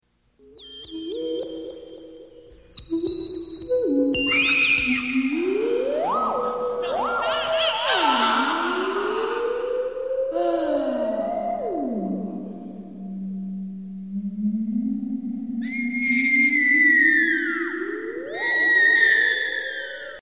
Ghost & Scream